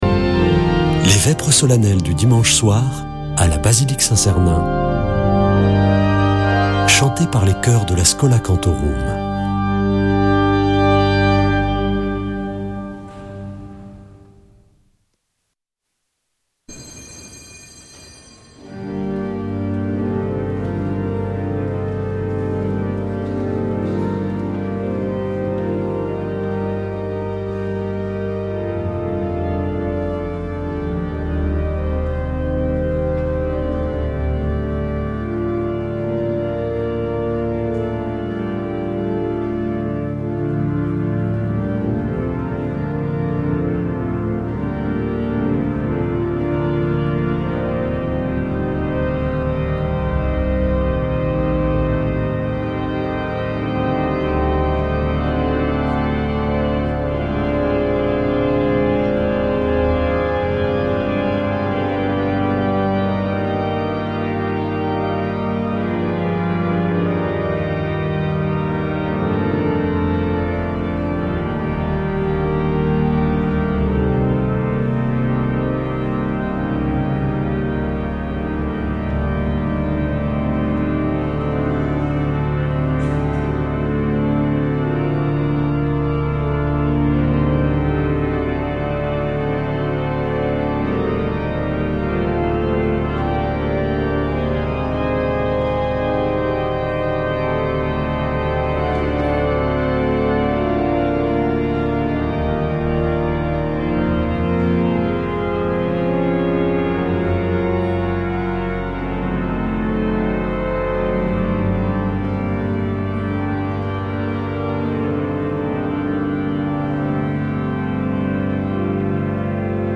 Accueil \ Emissions \ Foi \ Prière et Célébration \ Vêpres de Saint Sernin \ Vêpres de Saint Sernin du 13 avr.
Chanteurs